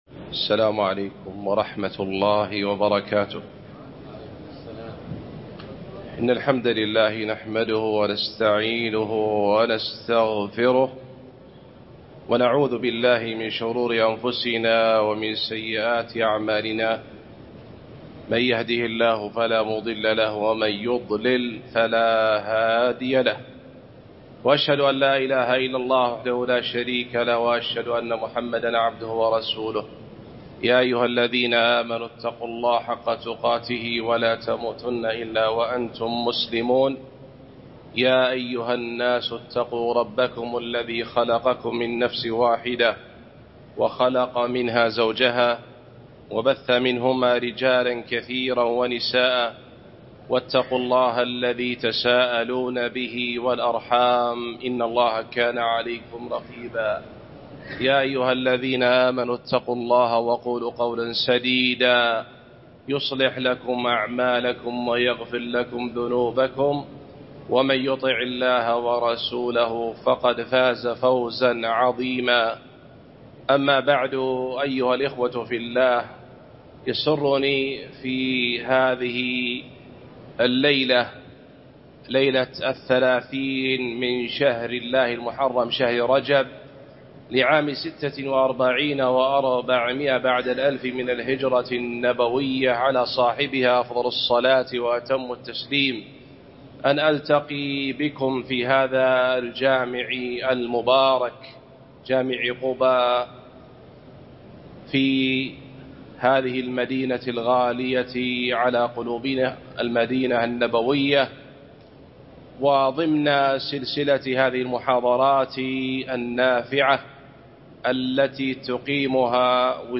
محاضرة التعليق على محاضرة تعظيم التوحيد في ضوء الكتاب و السنة